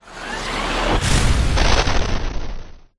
missile.mp3